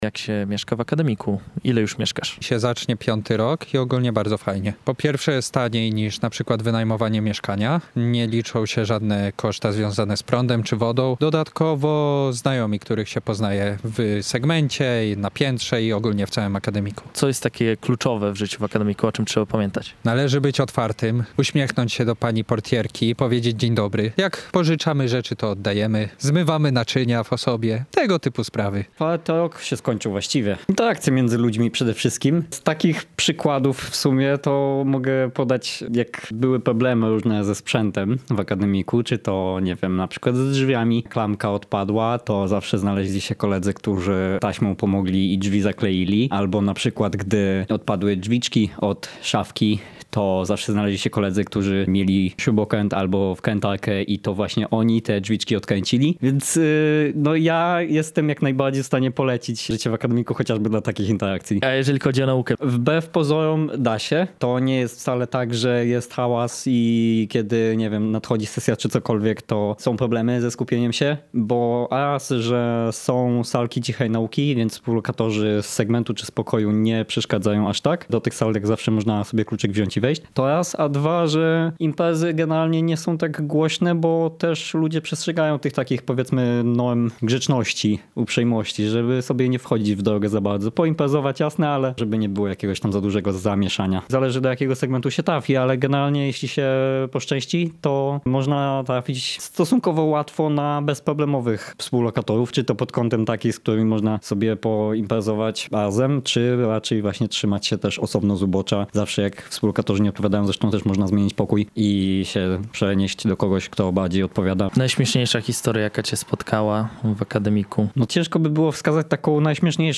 O to, jak się żyje w Kortowie, zapytaliśmy studentów.
MA-28.07-sonda-akademiki.mp3